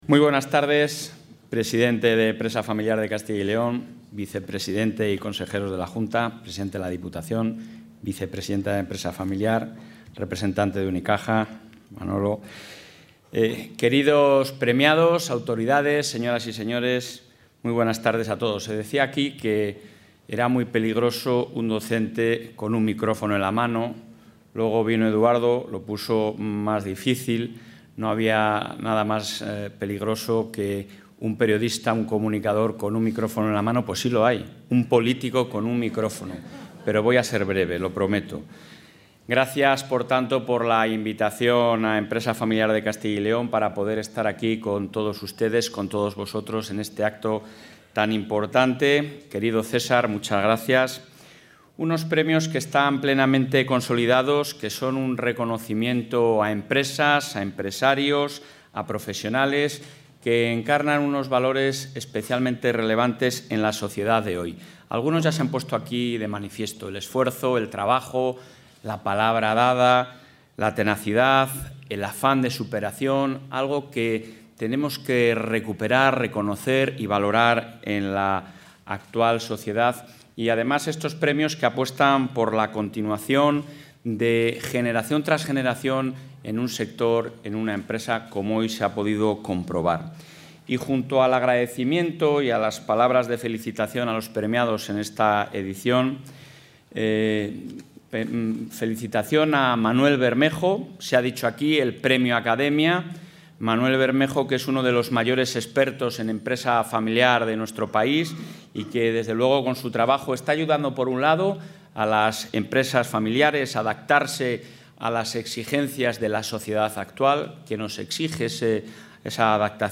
Intervención del presidente.